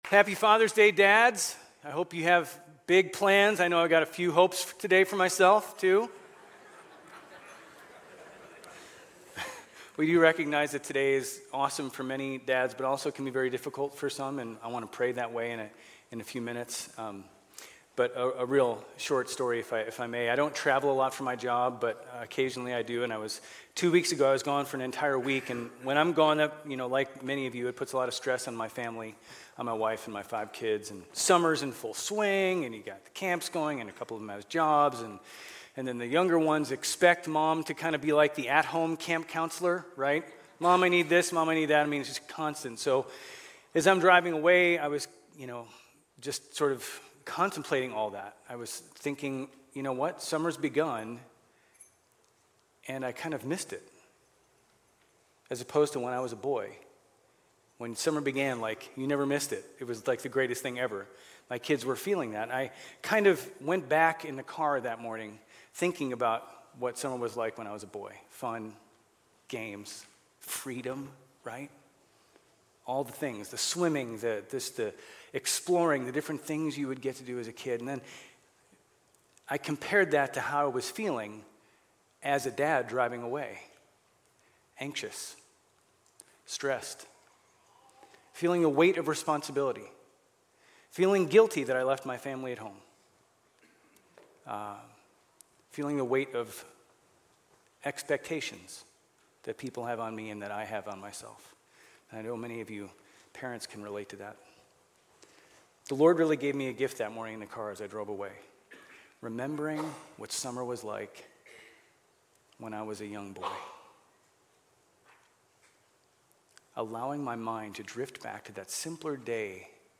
GCC-OJ-June-18-Sermon.mp3